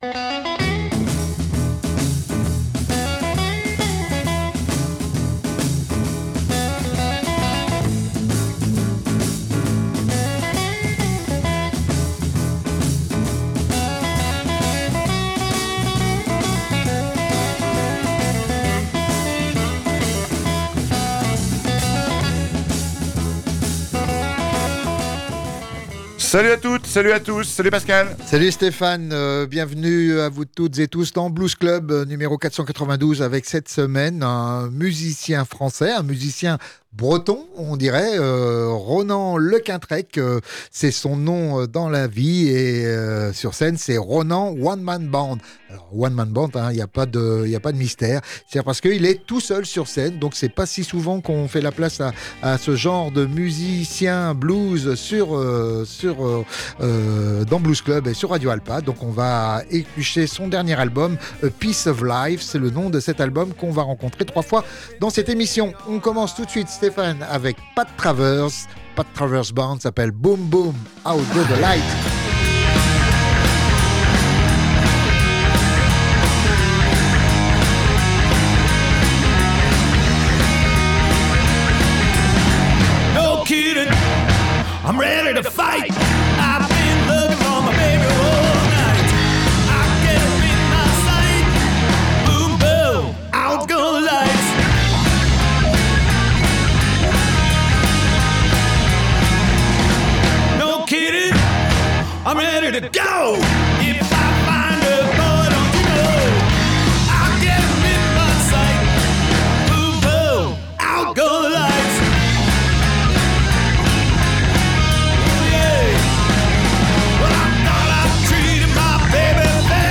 album intimiste et introspectif